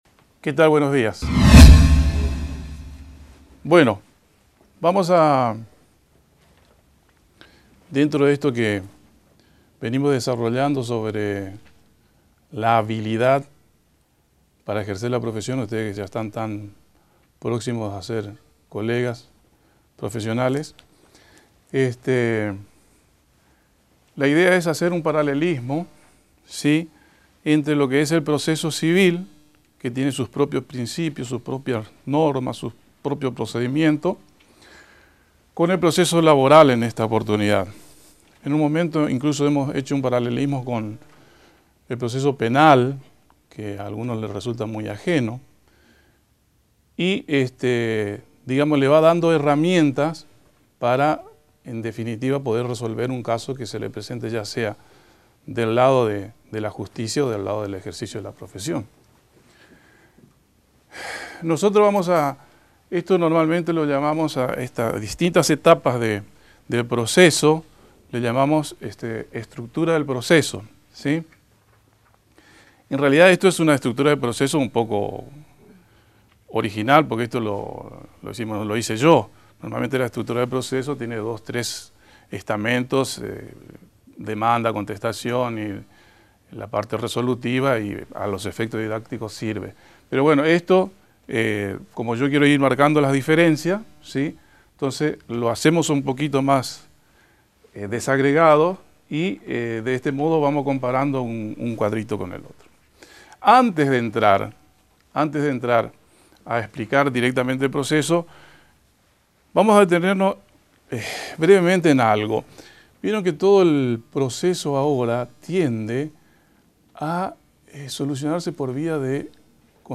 derecho-abierto-clase-Practica-Procesal-Civil-y-Laboral-S01.mp3